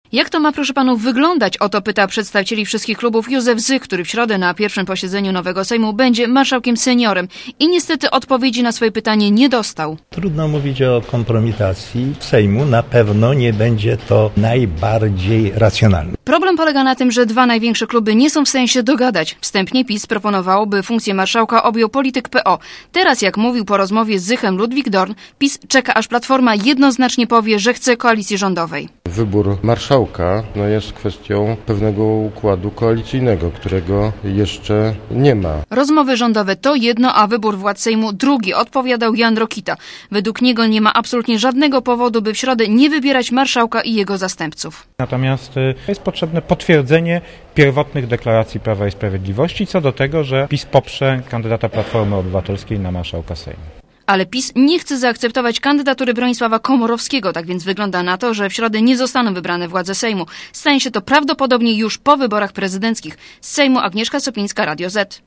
Źródło zdjęć: © RadioZet 17.10.2005 16:38 ZAPISZ UDOSTĘPNIJ SKOMENTUJ Relacja reportera Radia ZET